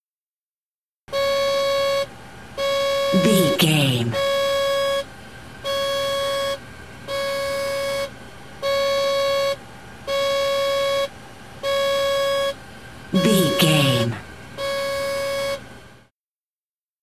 Airplane alarm cockpit
Sound Effects